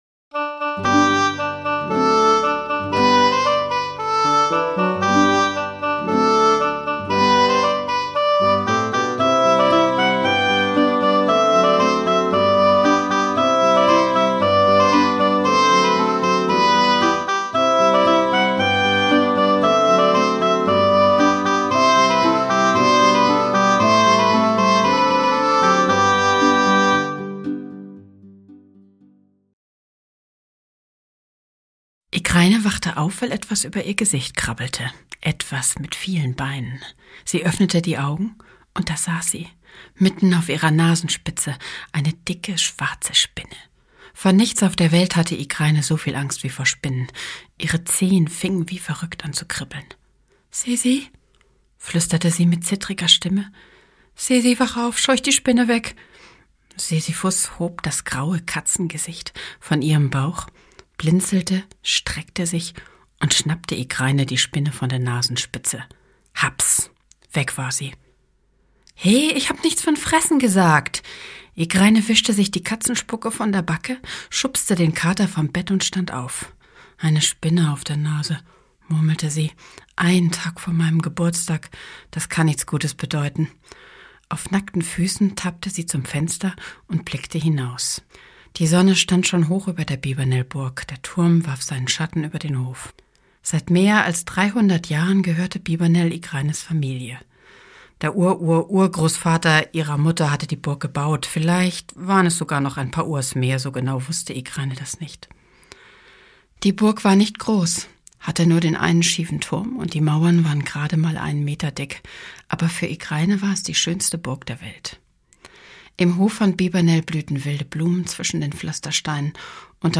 Cornelia Funke (Sprecher)
Eine spannende Rittergeschichte für Mädchen und Jungen. Einfühlsam erzählt von Cornelia Funke.